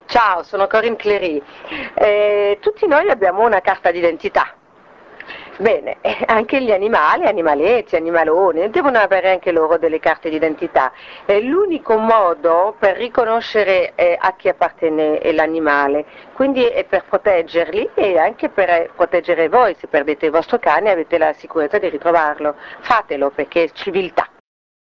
ASCOLTA GLI SPOT DI CORINNE CLERY
Spot 3 (Invito a tatuare i cani...)